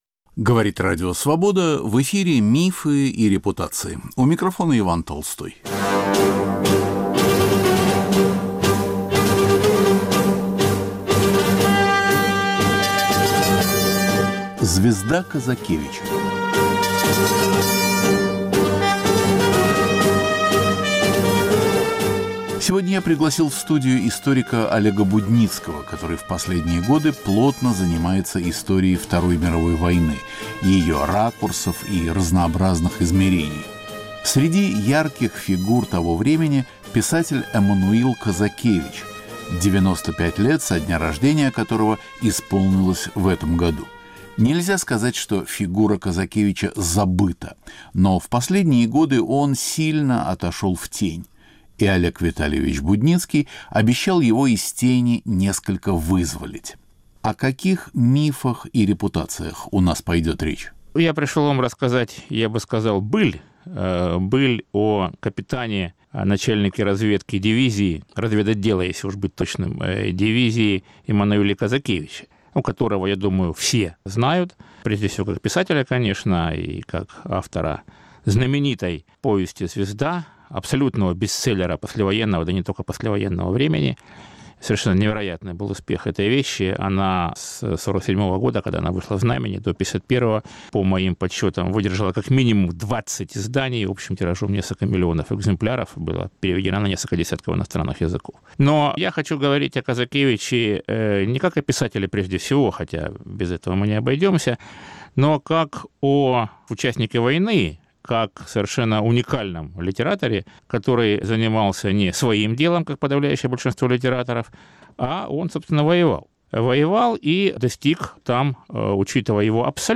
Разговор о полузабытом писателе, герое войны и мастере экзистенциальной советской прозы, авторе послевоенного бестселлера "Звезда". Рассказчик - историк Олег Будницкий.